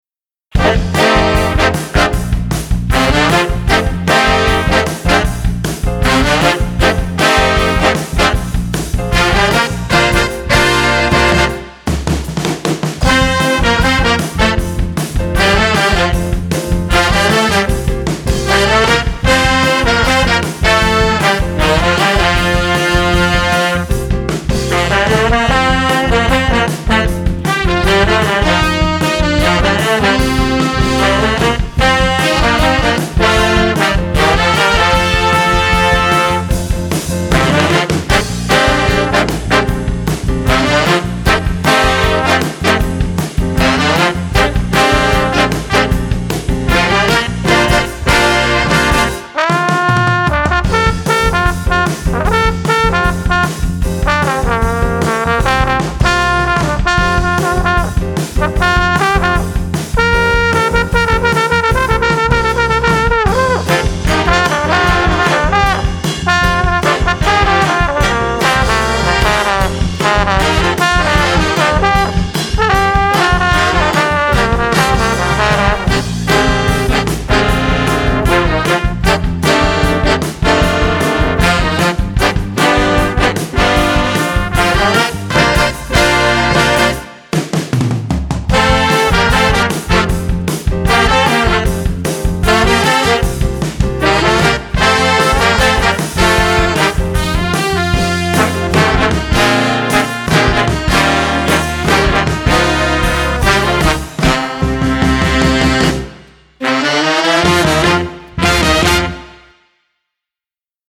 Voicing: Flex Jazz